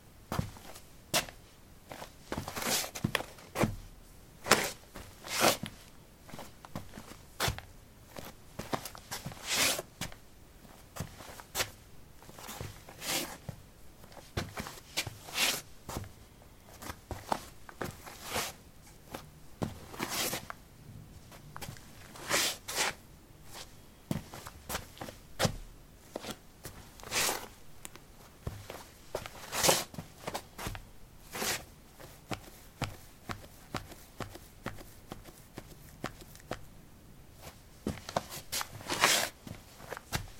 木头上的脚步声
描述：走在一个木露台的脚步。
标签： 脚步 效果 步骤 木材 跑步 散步 散步 声音
声道立体声